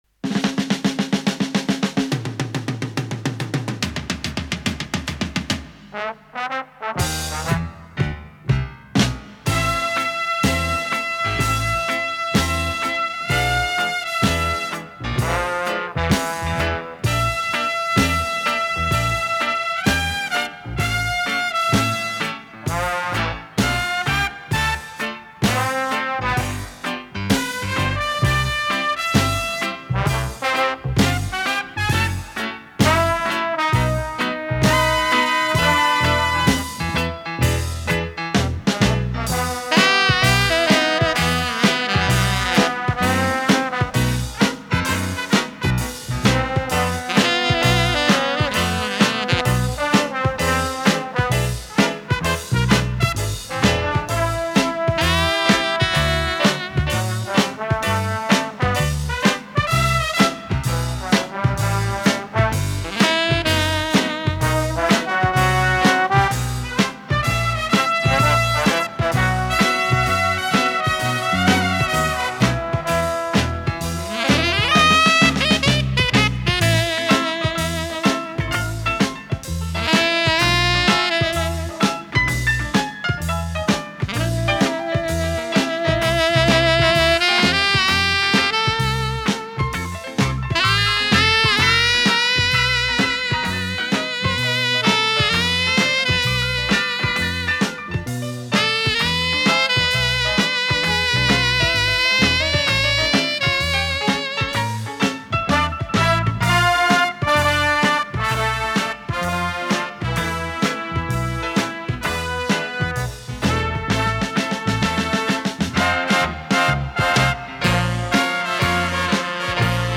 ретро-фокстрот